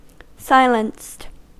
Ääntäminen
Vaihtoehtoiset kirjoitusmuodot (vanhahtava) silenc'd Ääntäminen US Haettu sana löytyi näillä lähdekielillä: englanti Käännöksiä ei löytynyt valitulle kohdekielelle.